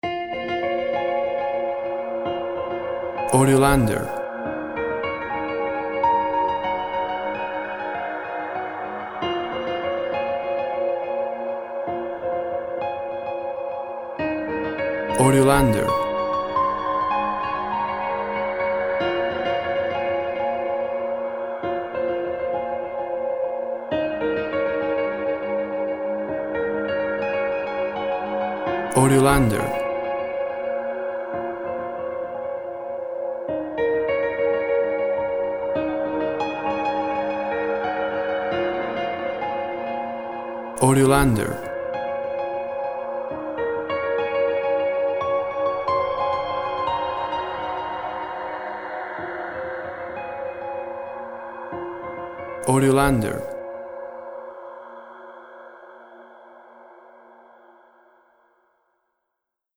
Light reverb piano for seaside or space.
Tempo (BPM) 70